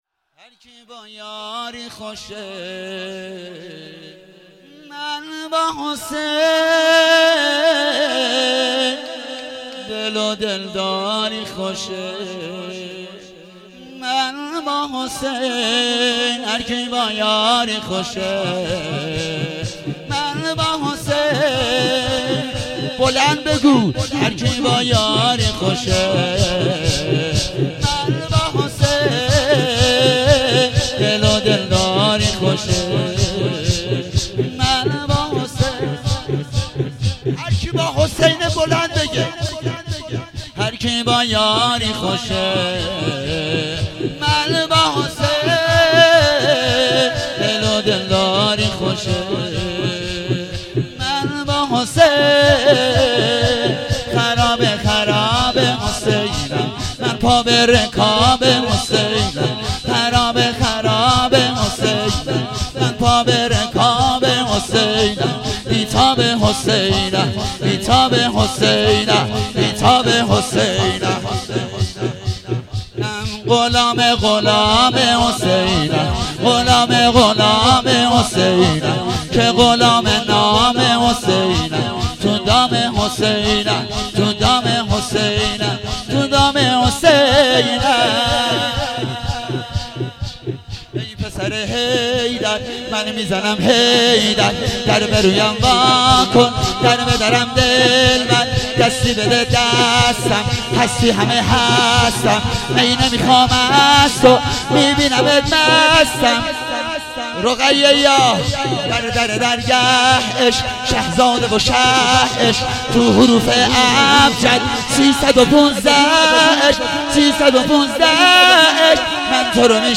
هفتگی 6 دی 97 - شور - هرکی با یاری خوشه